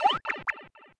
Sound effect of Pipe Slide Leave (World Map) (Alternative) in Super Mario Bros. Wonder
SMBW_Pipe_Slide_Leave_World_Map.oga